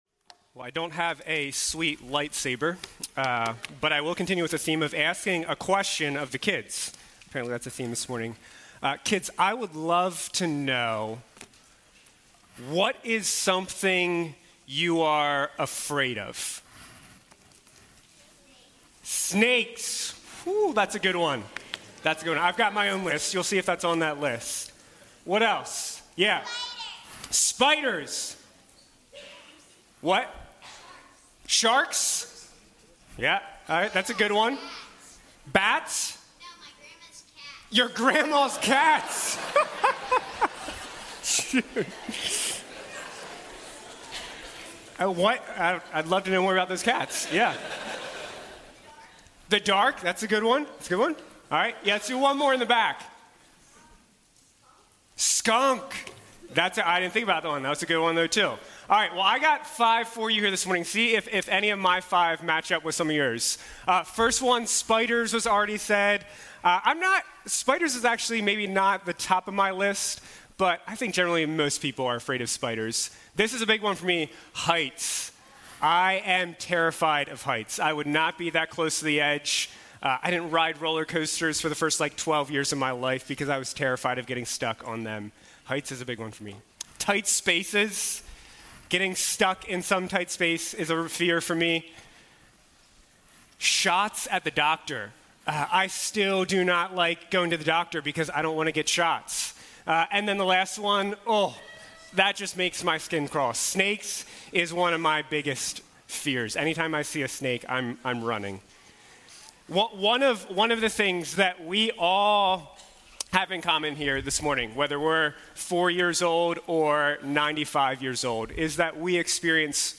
These are sermons delivered during the Sunday morning worship services of Keystone Church, an Evangelical Free Church in Paradise, PA, USA.